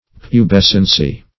\Pu*bes"cen*cy\